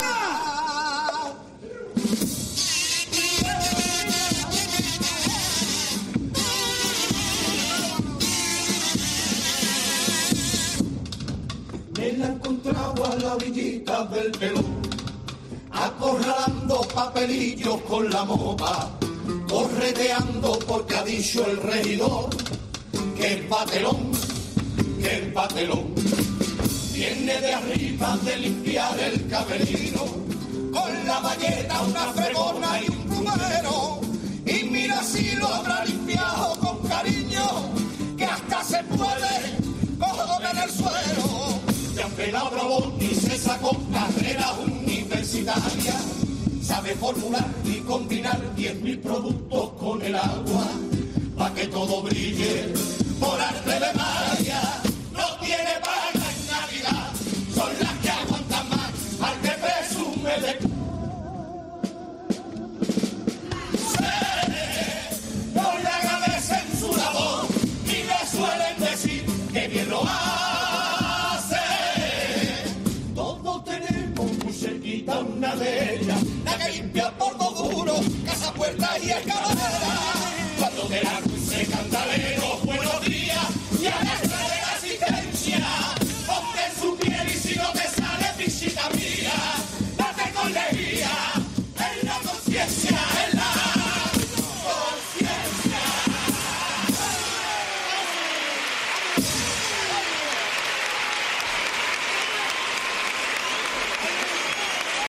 El pasodoble de la chirigota 'Amoescucha, chirigota callejera' a las limpiadoras
Es el mensaje final del primer pasodoble que la chirigota 'Amoescucha, chirigota callejera' interpretó en las tablas del Gran Teatro Falla en su pase de cuartos de final.
Sin duda una pasodoble más que sentido que encontró el enorme aplauso del Gran Teatro Falla.